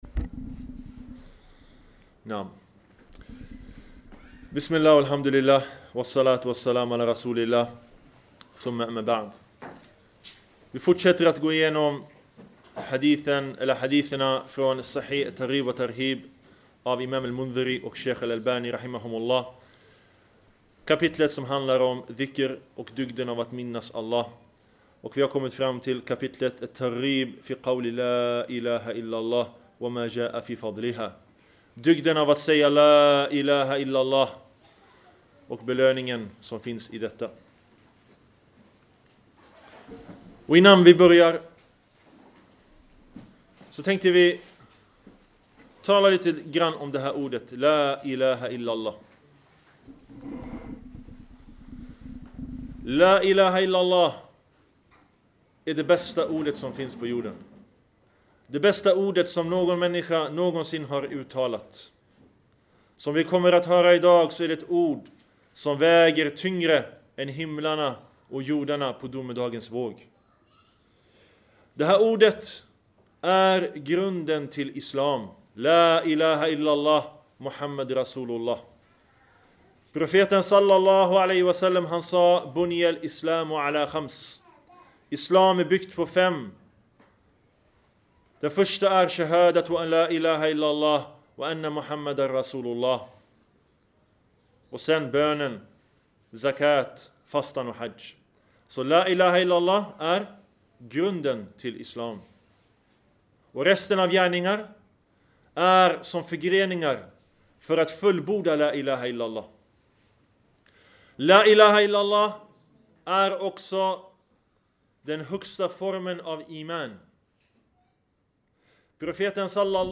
En föreläsning av